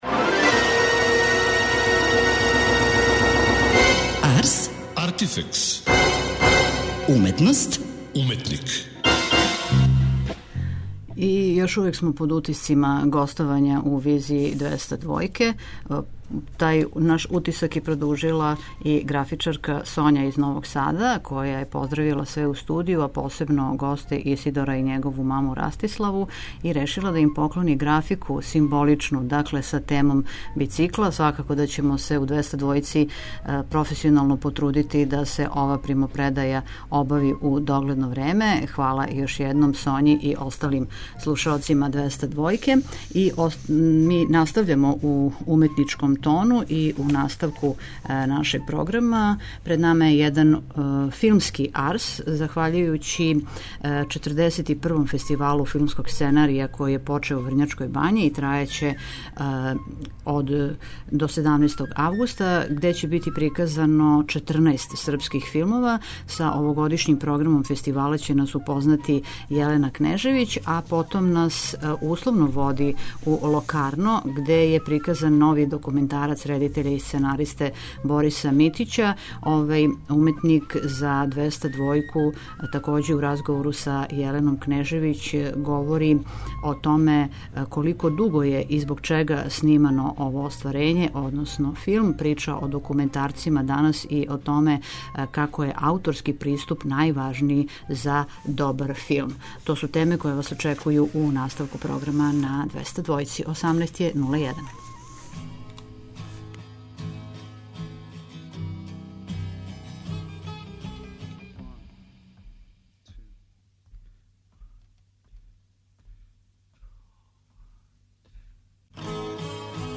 У оквиру овогодишњег издања Филмског фестивала у Локарну у Швајцарској нашле су се нове режије српских аутора, а у емисији доносимо разговор са једним од њих.